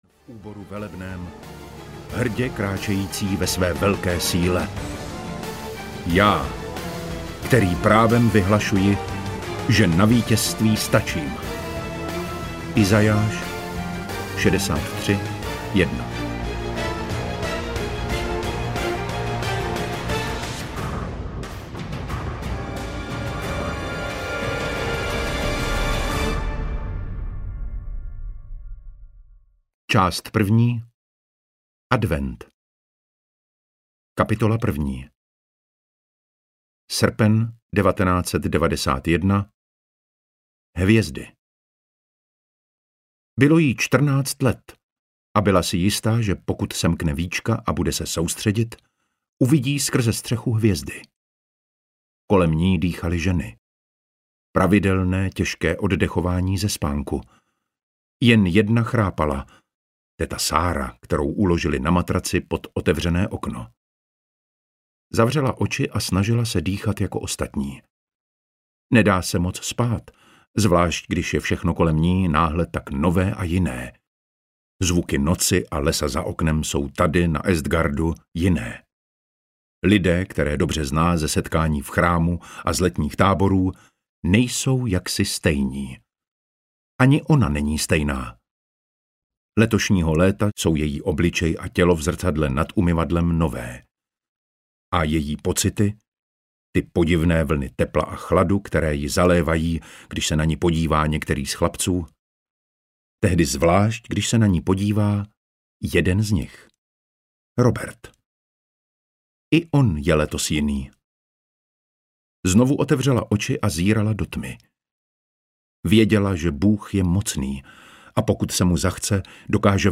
Spasitel audiokniha
Ukázka z knihy
• InterpretDavid Matásek